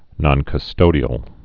(nŏnkŭ-stōdē-əl)